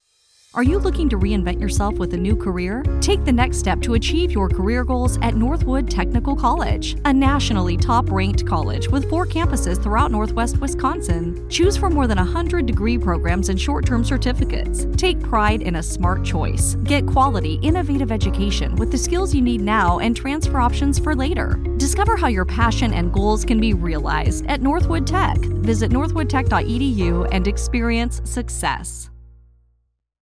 Northwood Tech radio ad